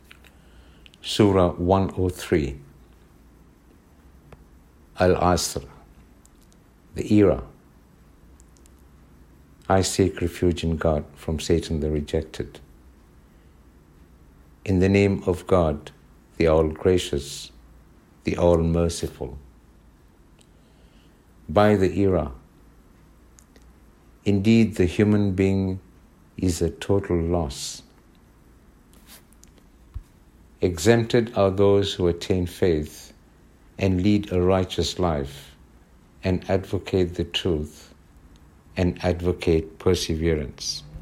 Quran English Reading